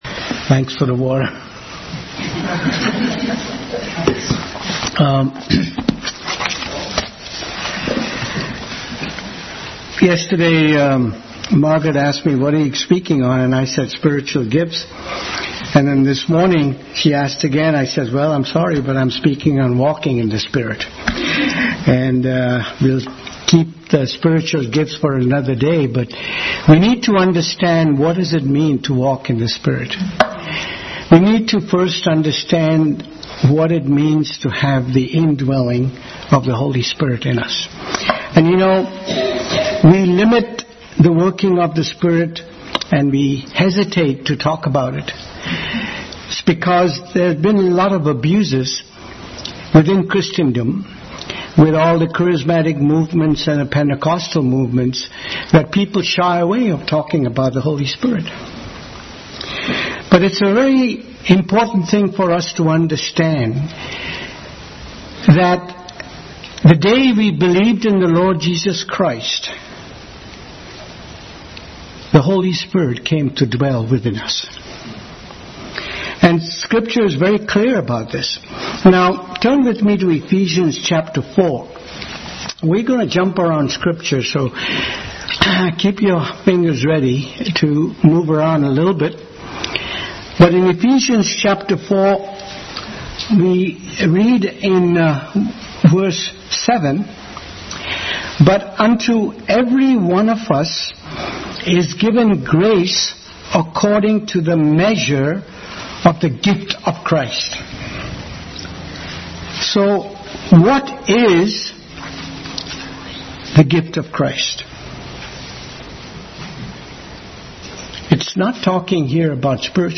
Family Bible Hour Message – Walking in the Spirit.